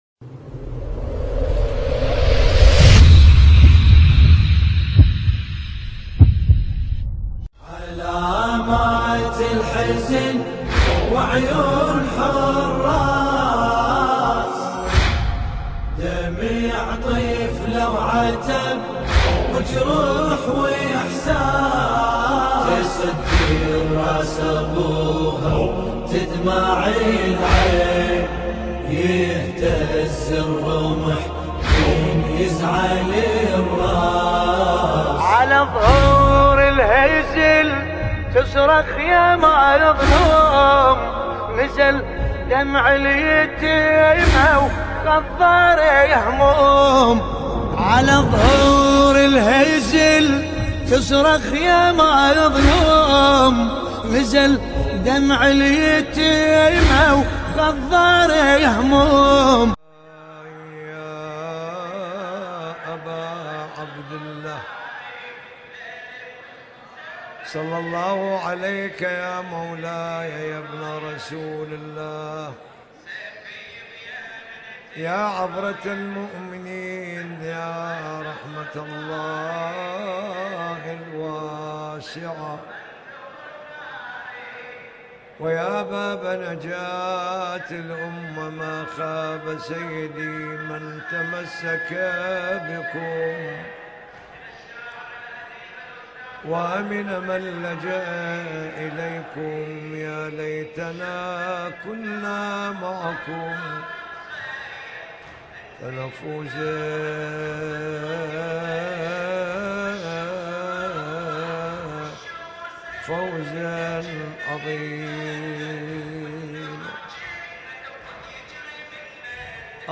السيد جاسم الطويرجاوي 20 صفر 1433هـ – العتبة الحسينية